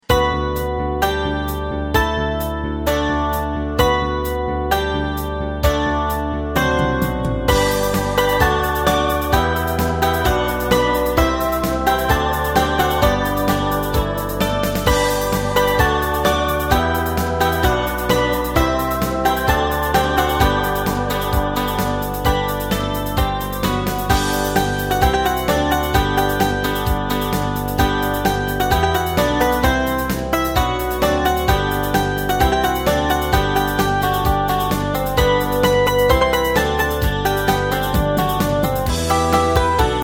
Ensemble musical score and practice for data.
Category: ensemble .